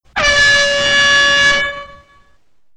air_horn.wav